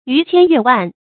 逾千越万 yú qiān yuè wàn 成语解释 指数字超过千或万。
成语注音 ㄧㄩˊ ㄑㄧㄢ ㄩㄝˋ ㄨㄢˋ